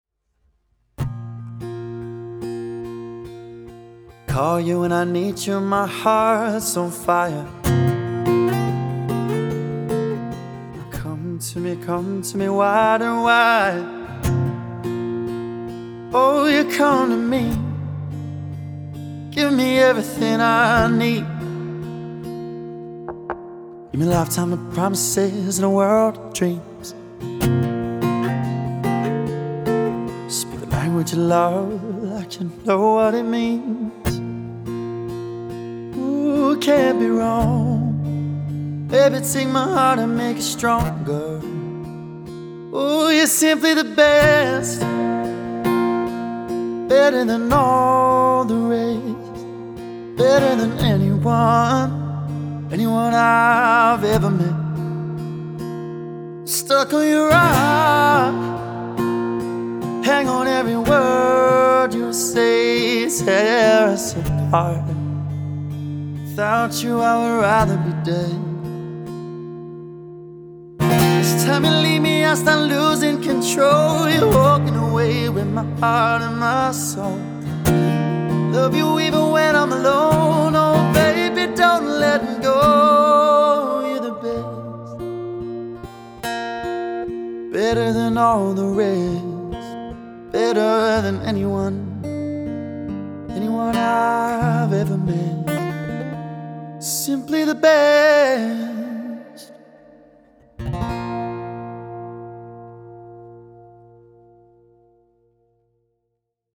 Vocals | Guitar | Piano | Looping | DJ | MC